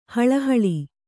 ♪ haḷahaḷi